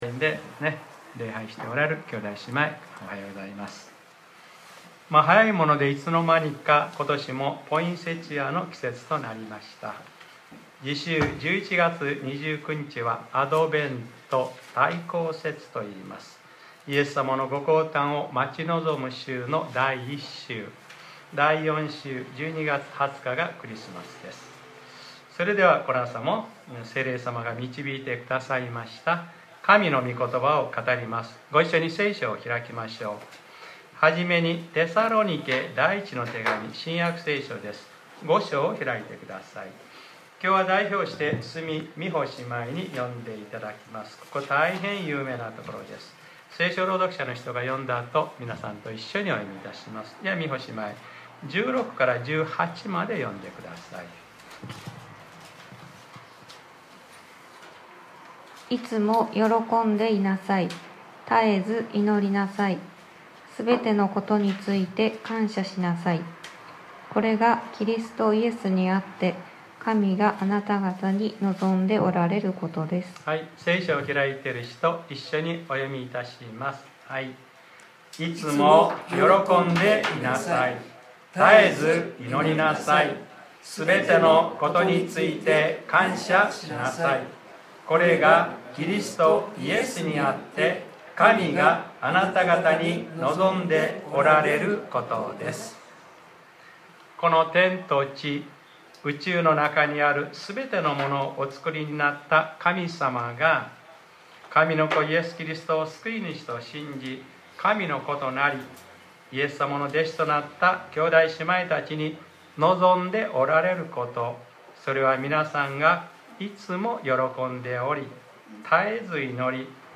2020年11月22日（日）礼拝説教『神が望んでおられること』